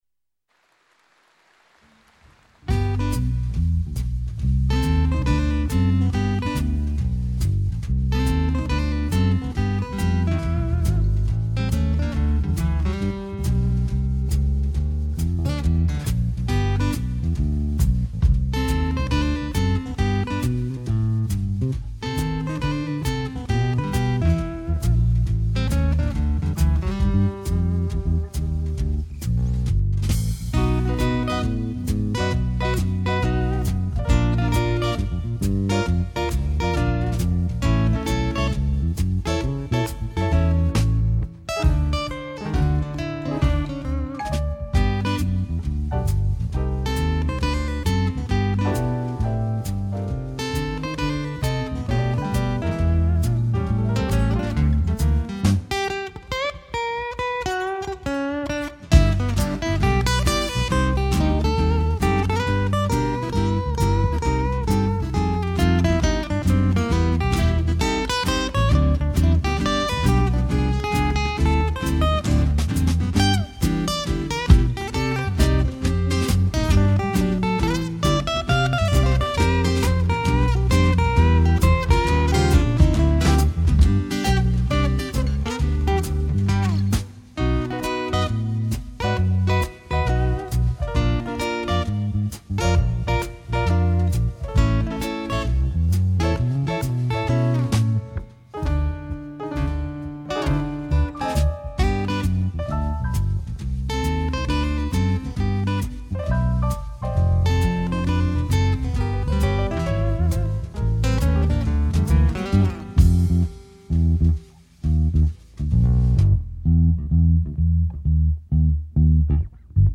Qualité image et prise de son top niveau.
en live